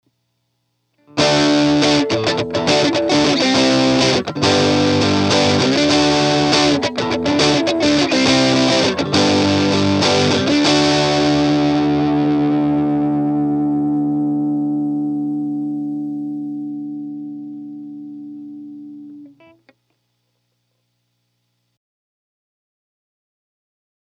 Overdriven with Tube Screamer for Extra Drive (Gibson Nighthawk 2009)
1. Mic head-on, dead-center
champ_rec_dirty_ho.mp3